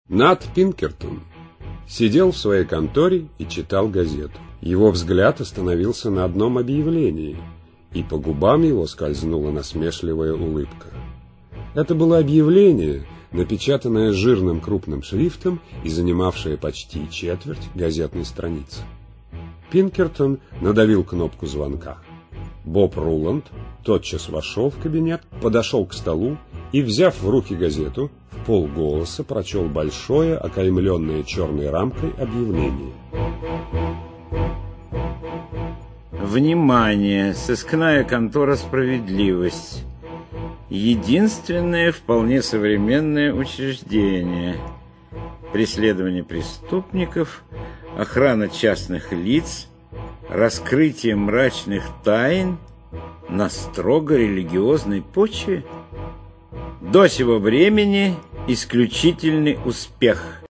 Аудиокнига Приключения сыщика Ната Пинкертона. Аудиоспектакль | Библиотека аудиокниг